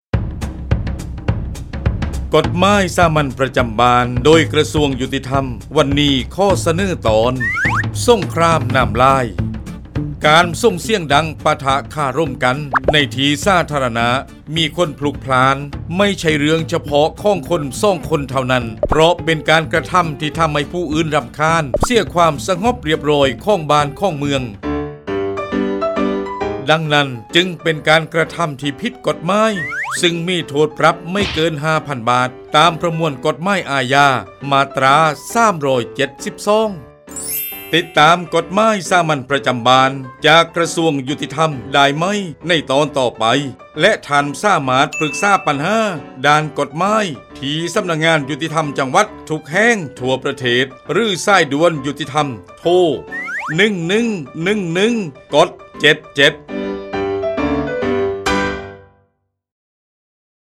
กฎหมายสามัญประจำบ้าน ฉบับภาษาท้องถิ่น ภาคใต้ ตอนสงครามน้ำลาย
ลักษณะของสื่อ :   คลิปเสียง, บรรยาย